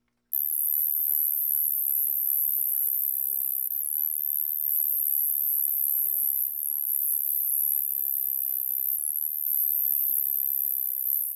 Two males of C. monstrosa calling.
here to listen to the recording (note: if, like me, you are an older male, you may need to crank up the volume quite high!)
cyphoderris_monstrosa2.wav